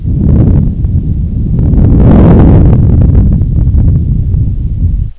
Ambient Sounds
Wind
caves.wav